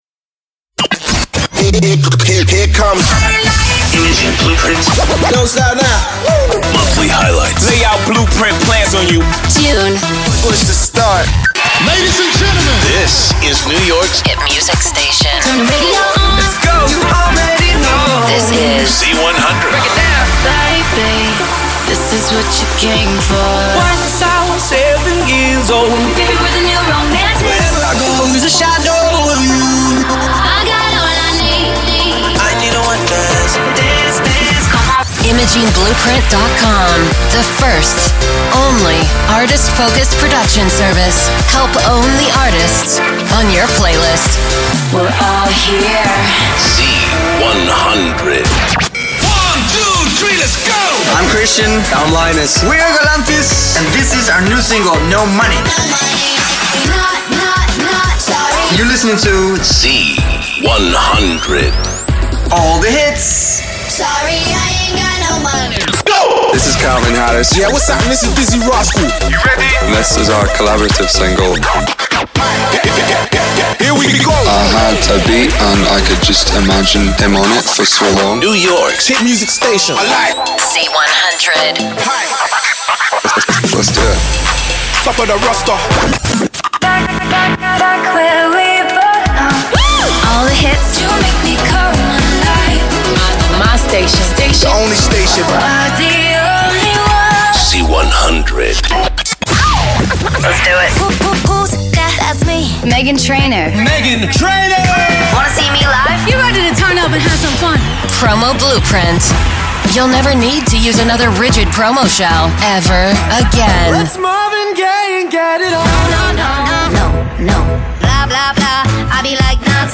It features an innovative, online database containing thousands of audio files including sound design, music beds, artist-imaging workparts, vocal work parts, topical elements and much much more! This is a small demonstration of audio uploaded to Imaging Blueprint throughout the month of June 2016.